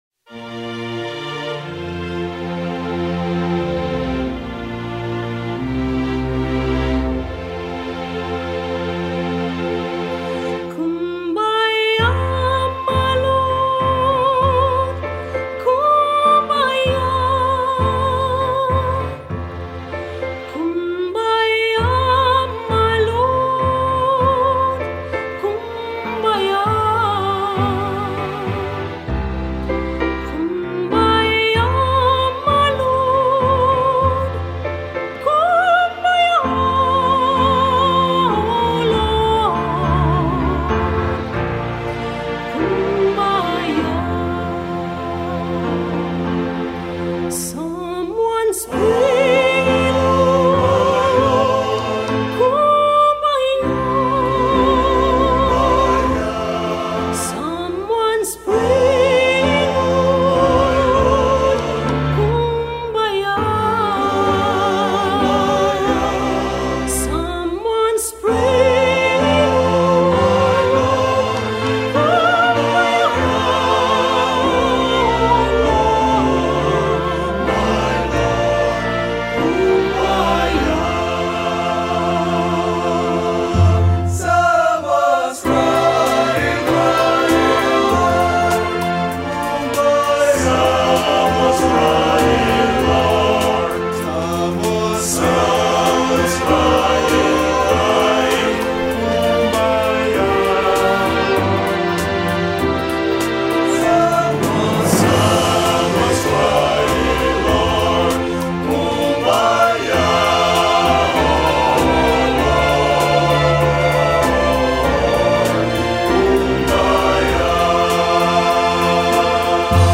1053   03:04:00   Faixa:     Canção Religiosa